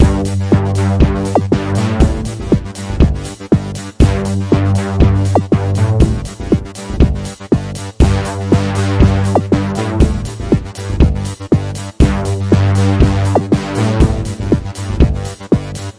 描述：由混合工艺制成的Pizzicato Chill
标签： 120 bpm Rap Loops Strings Loops 697.42 KB wav Key : Unknown
声道立体声